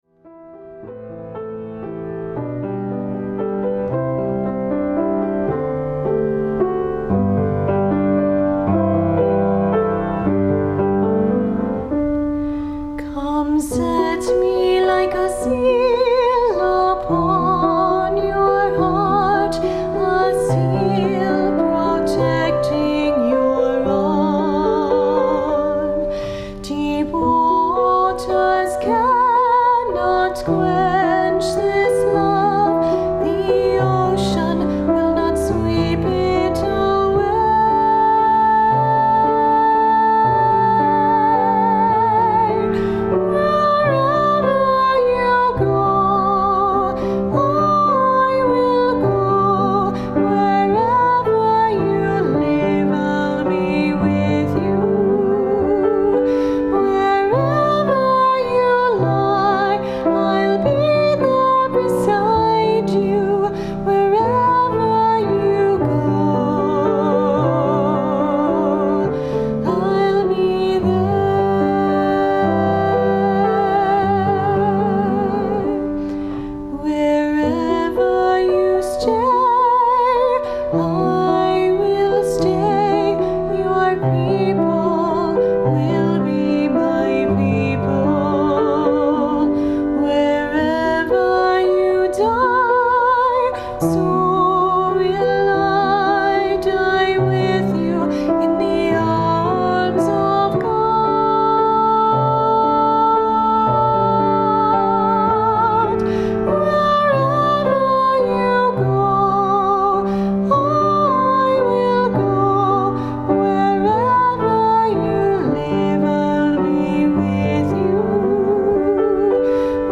vocal
piano